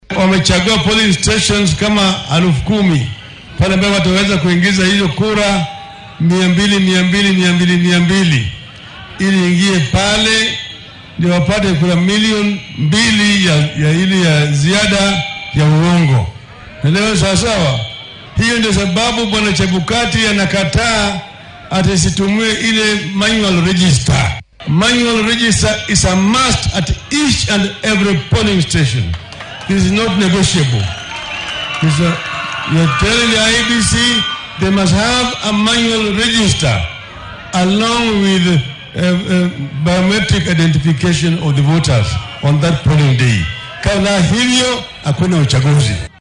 Mr. Odinga ayaa xilli uu ku sugnaa magaalada Githunguri ee ismaamulka Kiambu sidatan yiri.